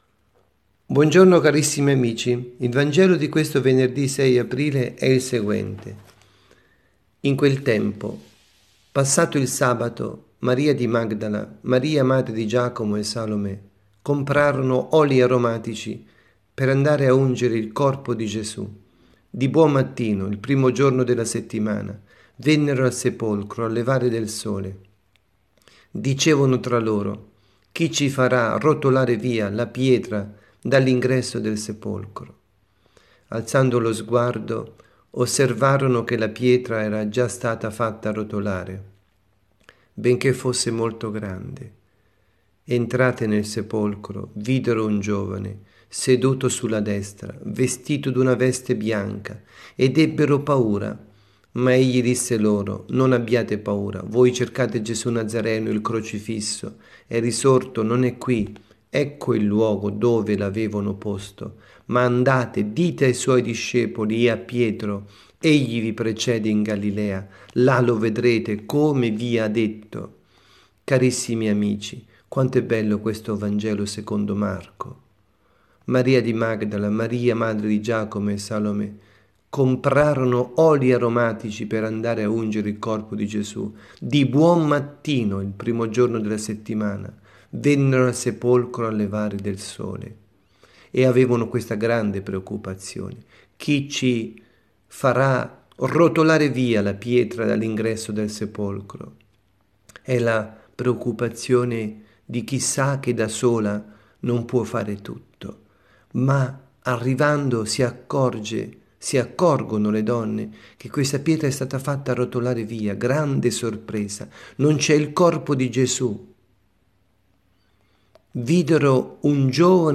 Catechesi
dalla Parrocchia S. Rita – Milano